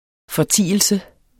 Udtale [ fʌˈtiˀəlsə ]